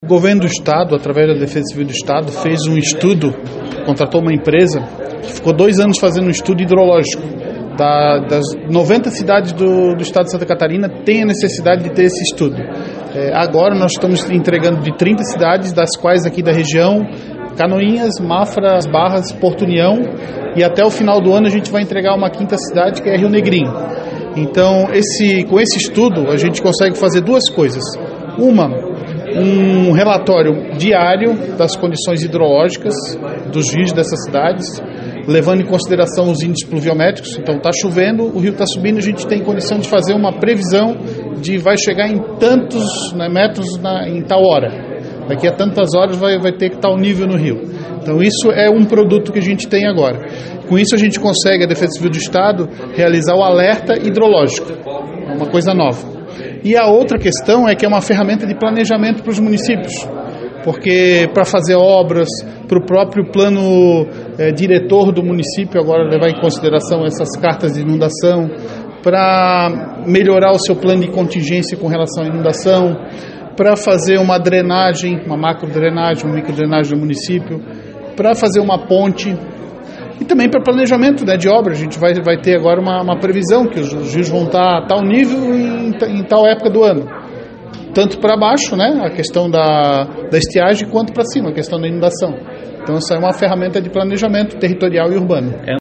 O trabalho da Defesa Civil de fazer a Carta de Inundação, teve como objetivo dar uma direção as equipes locais no caso de ações rápidas para ajudar a sociedade em momentos de chuva e pós chuva em áreas de risco. A entrega dos documentos foi feita pelo chefe da Defesa Civil do Estado de Santa Catarina, João Batista Carneiro Junior, que destacou a importância do material para a equipe da Defesa Civil, acompanhe: